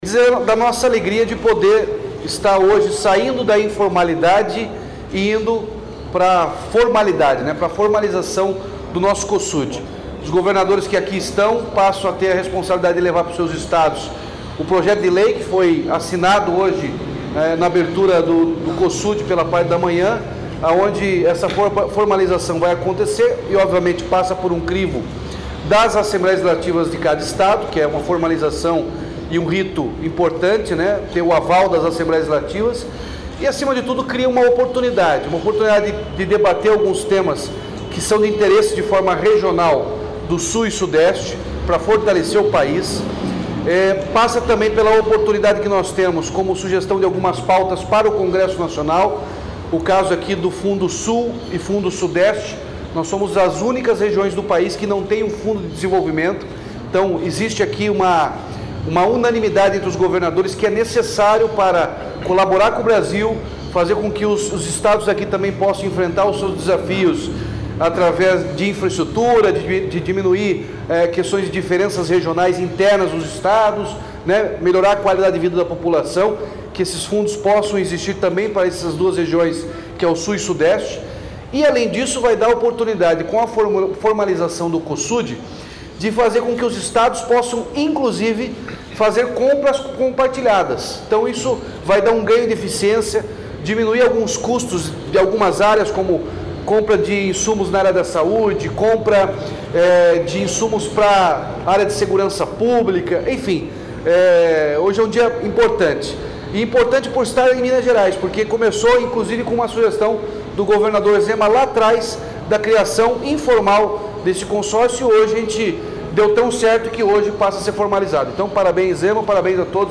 Sonora do governador Ratinho Junior sobre a formalização do Cosud durante o 8º encontro do Consório, em Belo Horizonte